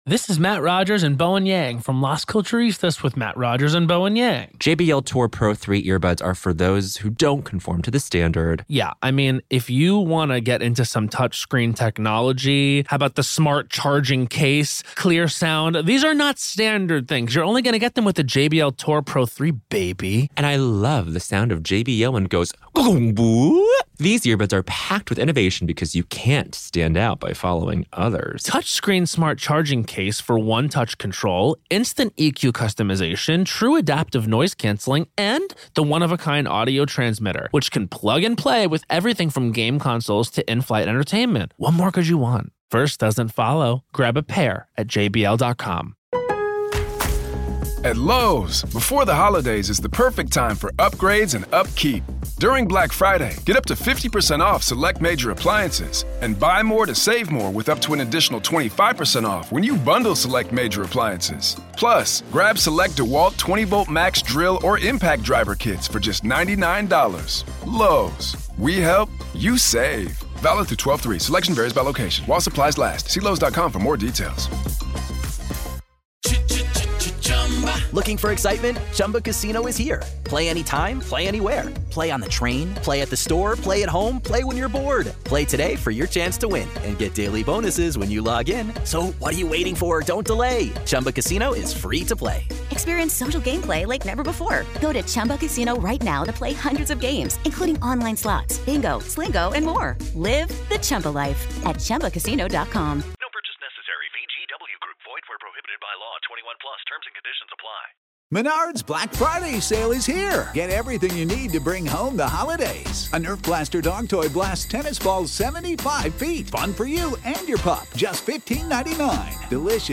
What followed was an account of spectral sightings, inexplicable sounds, and an overwhelming sense of loss that hung over the crash site. In this special, uninterrupted classic episode of The Grave Talks , we bring you that conversation in its entirety.